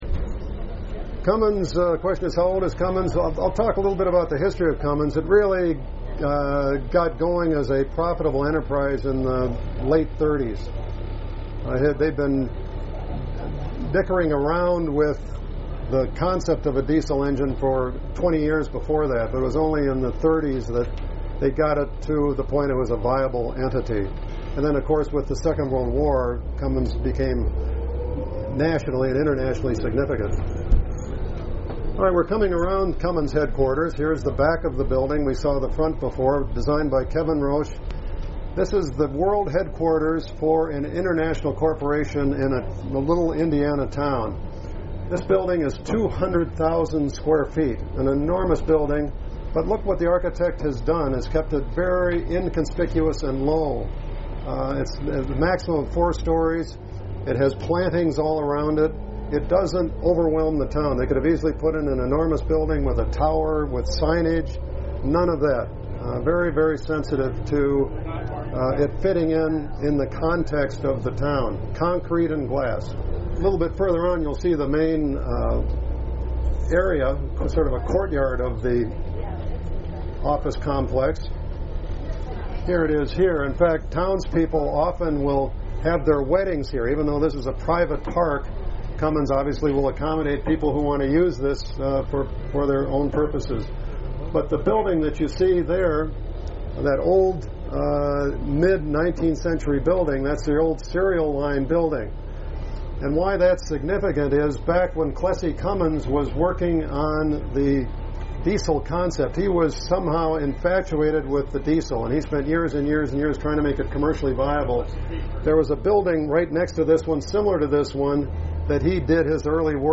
Tour Guide